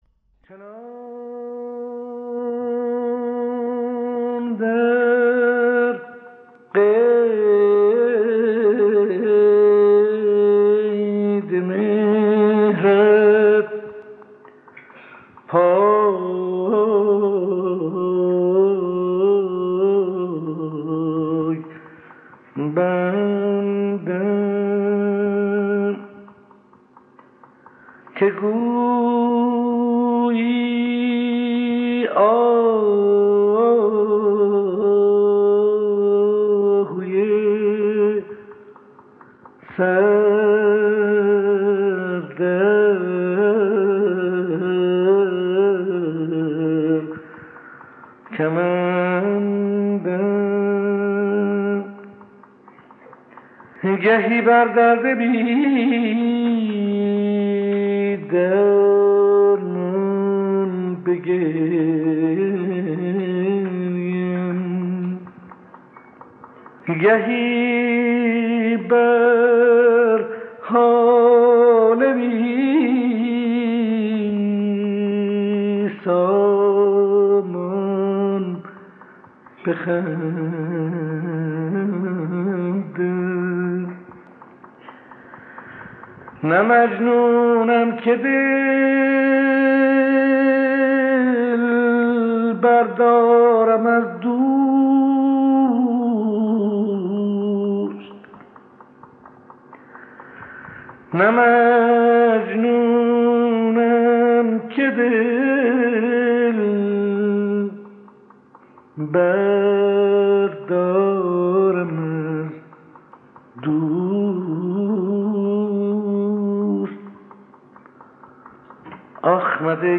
اجرای خصوصی